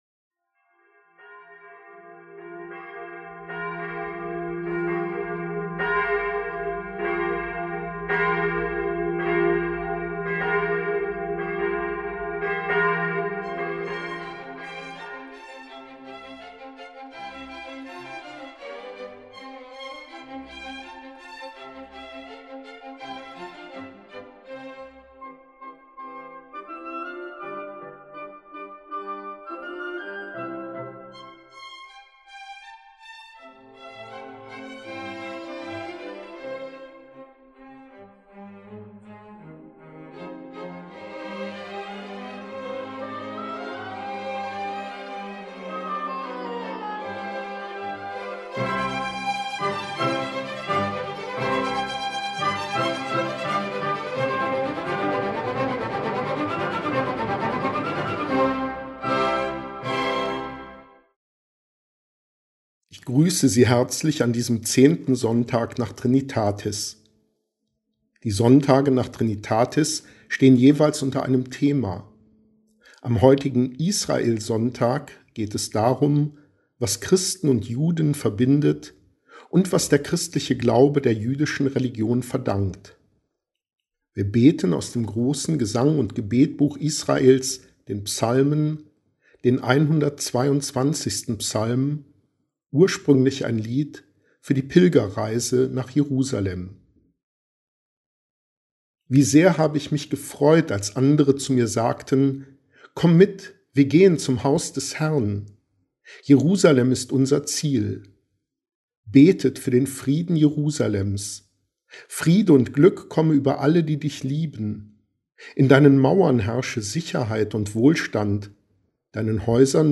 Hörpredigt zum 12. Sonntag nach Trinitatis 2020